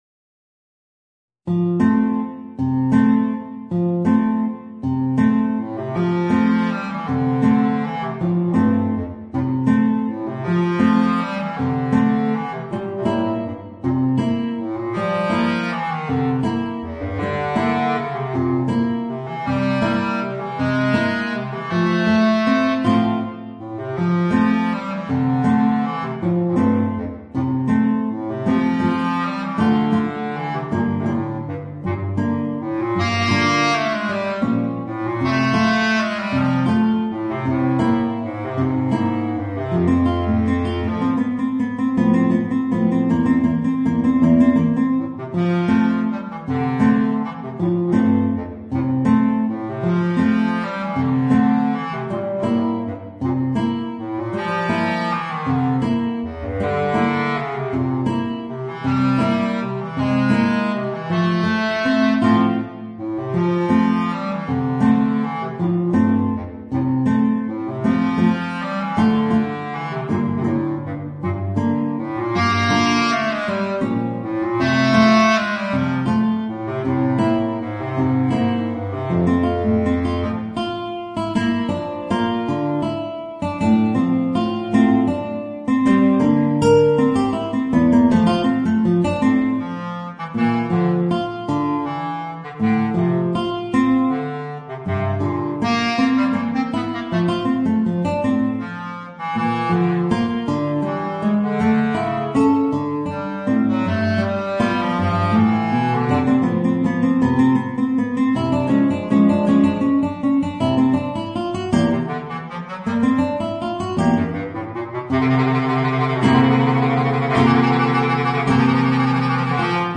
Voicing: Bass Clarinet and Guitar